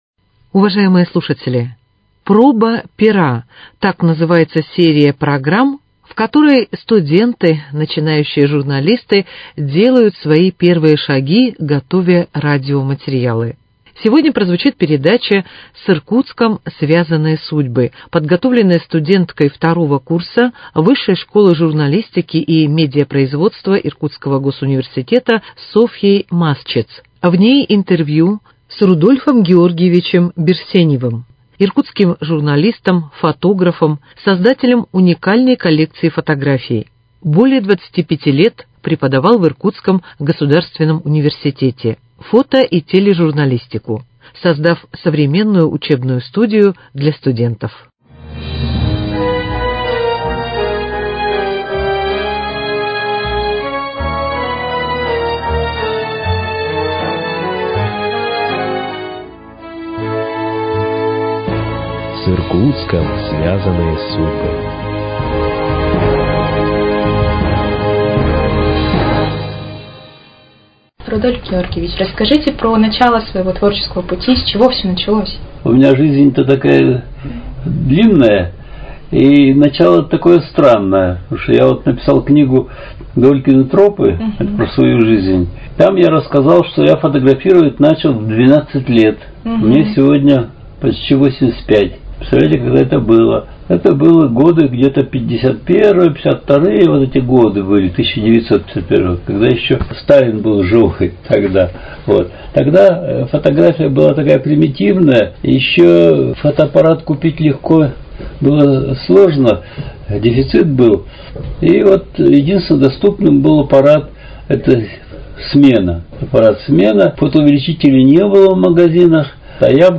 С Иркутском связанные судьбы: Беседа